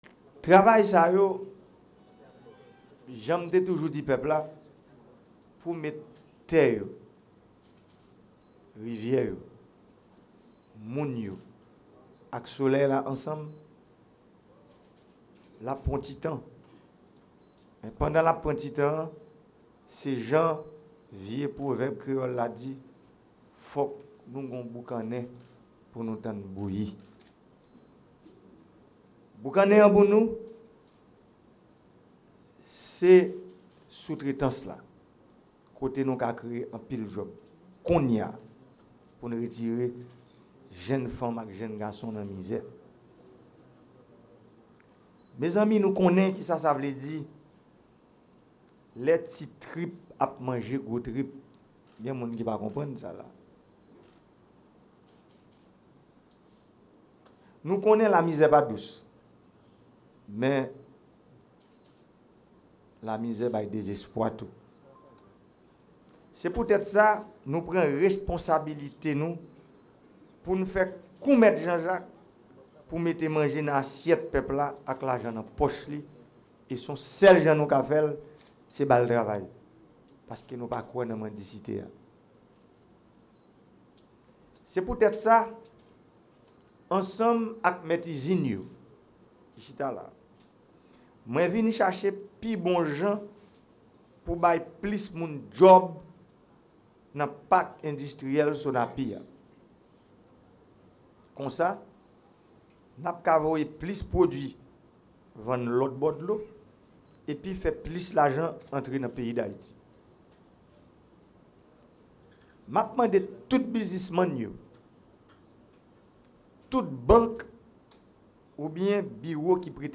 AUDIO – La Société Nationale des Parcs Industriels a reçu ce mardi la visite du Président de la république.
Dans ses propos, le Chef de l’Etat a plaidé en faveur de la nécessité d’accorder une attention particulière à ce secteur ; vue les multiples possibilités de créations d’emploi y existant.